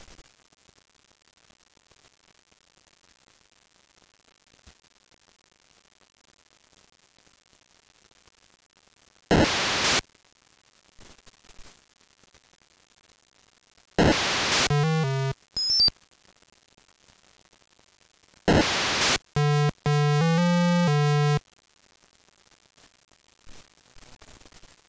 This is the Kobold Battle sound from the TI-99/4A video game Tunnels of Doom.